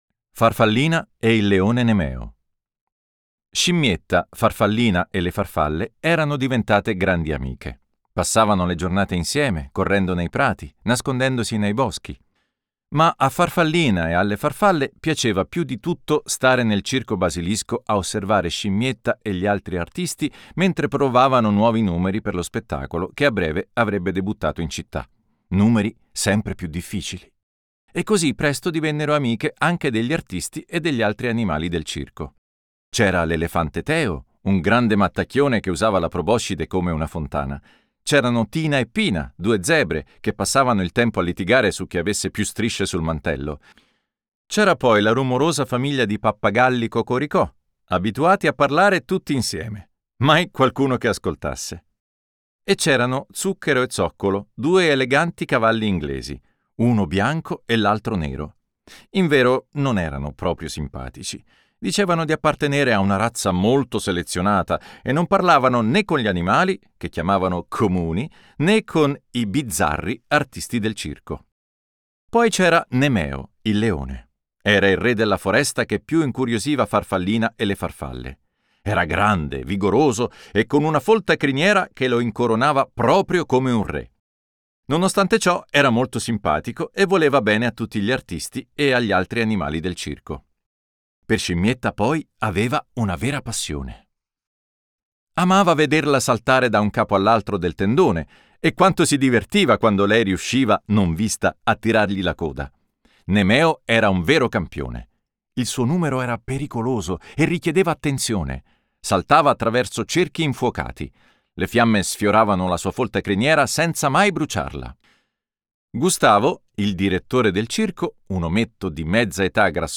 Voce di Neri Marcorè